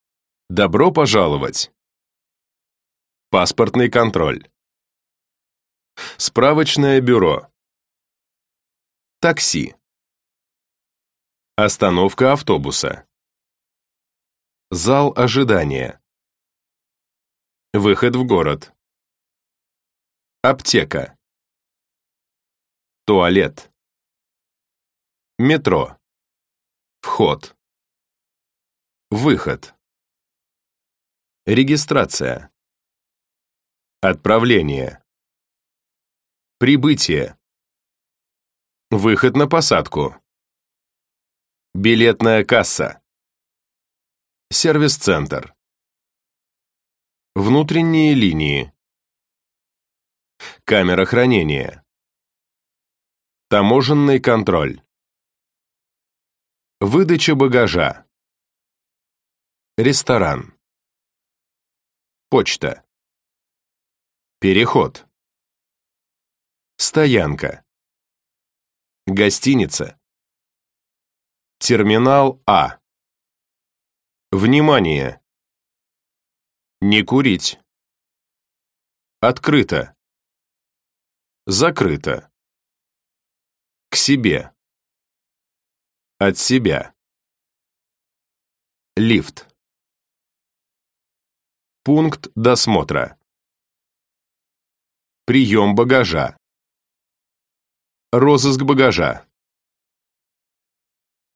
Аудиокнига Занимательный русский | Библиотека аудиокниг
Прослушать и бесплатно скачать фрагмент аудиокниги